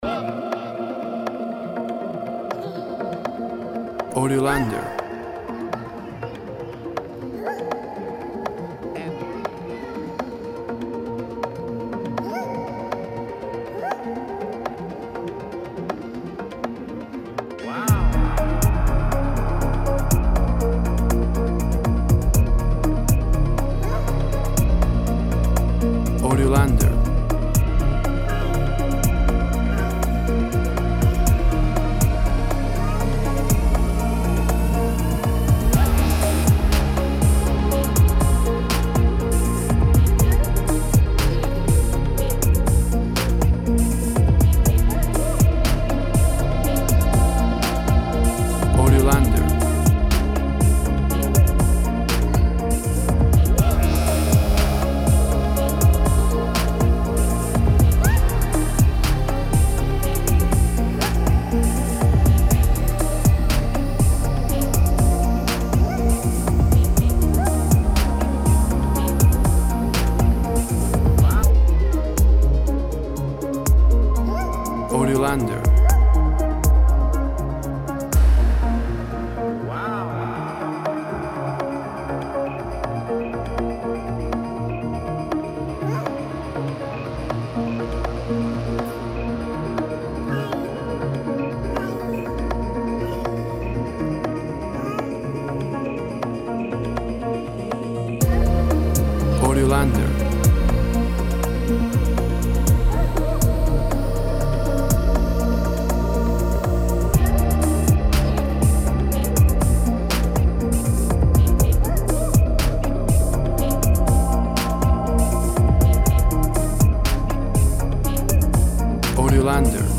Tempo (BPM) 121